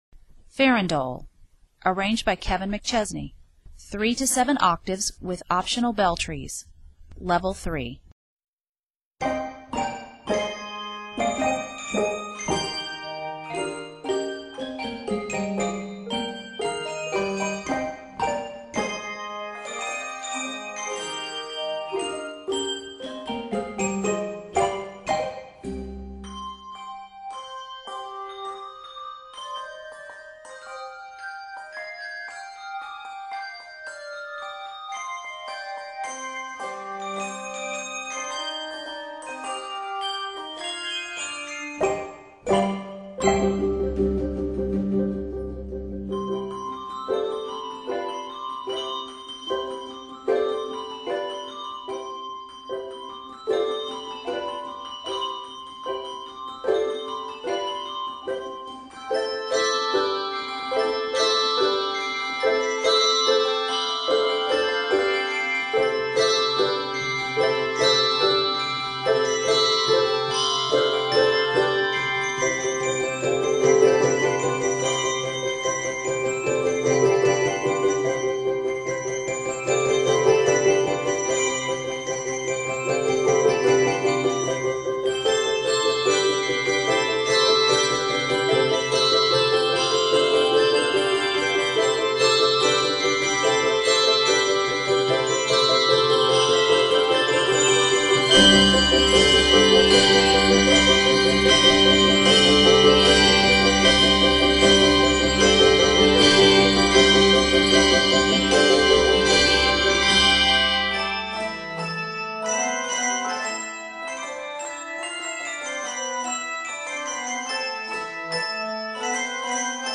3-7 octaves of handbells and two optional belltrees